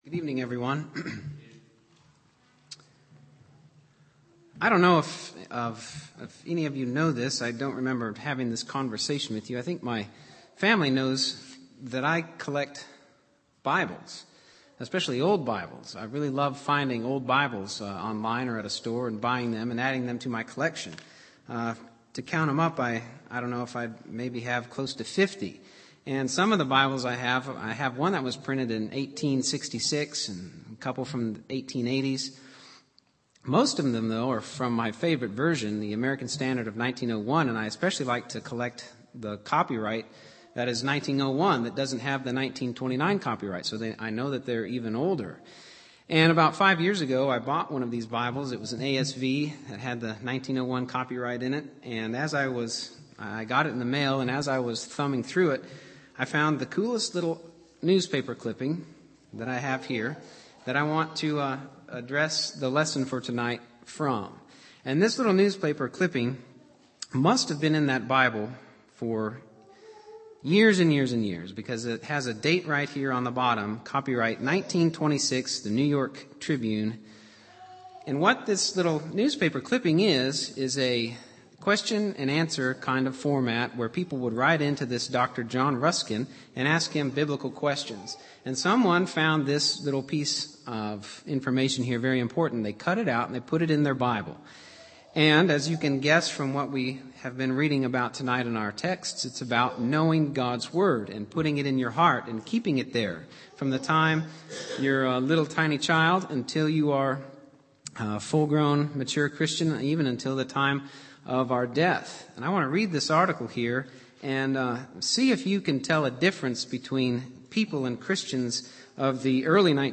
Sermons From The New Testament